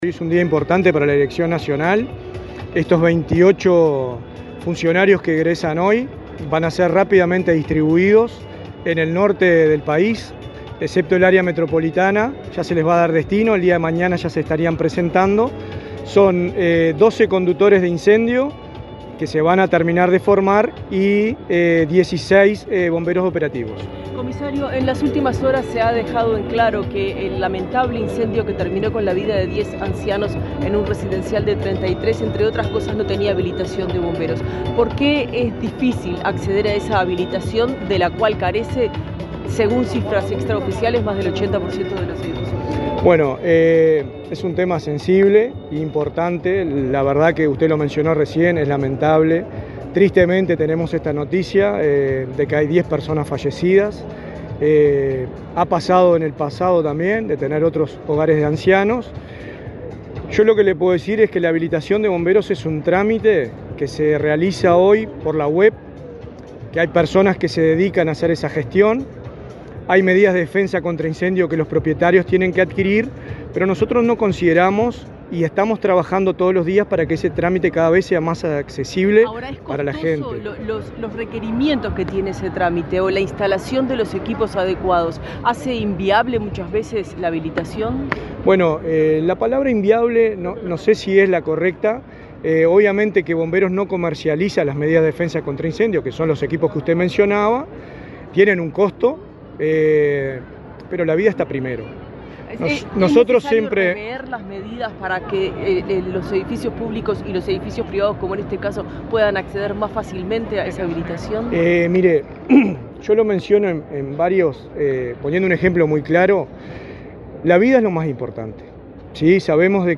Declaraciones a la prensa del encargado de la Dirección Nacional de bomberos, Richard Barboza
El encargado de la Dirección Nacional de bomberos, Richard Barboza, dialogó con la prensa, luego de encabezar la ceremonia de egreso de alumnos de